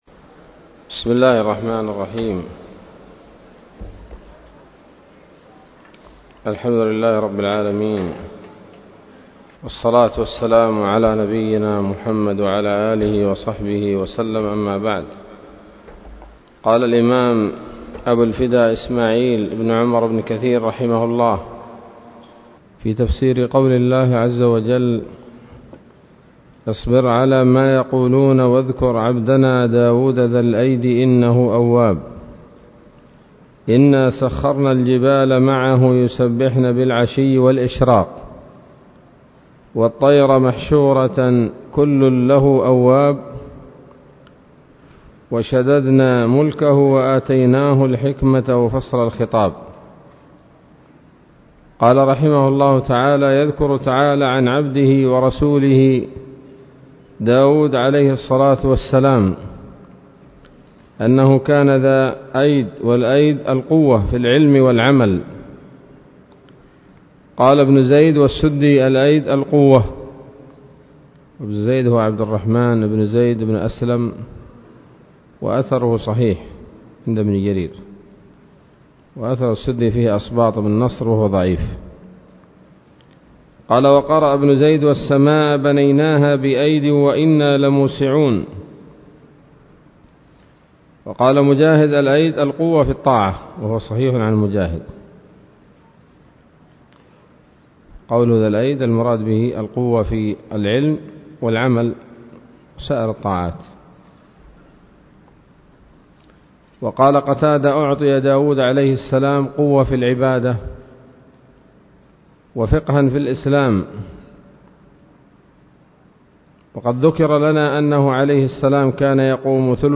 الدرس الثالث من سورة ص من تفسير ابن كثير رحمه الله تعالى